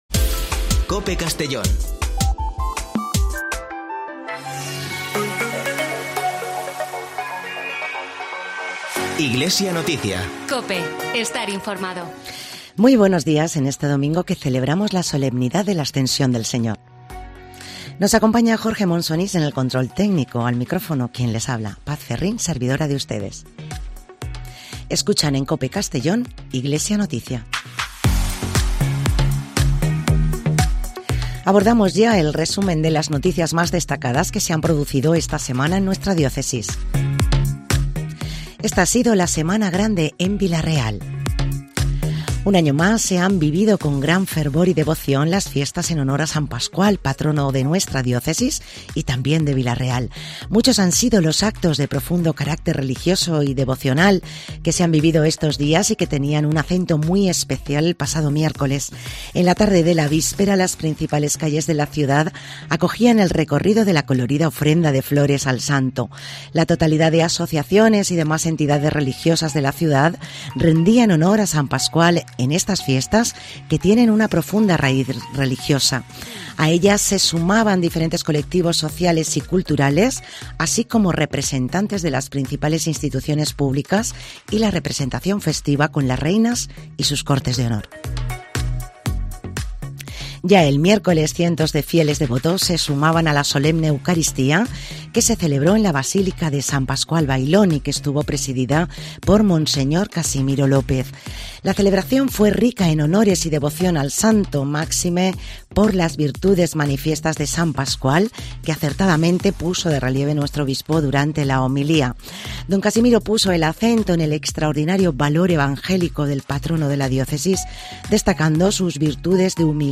Espacio informativo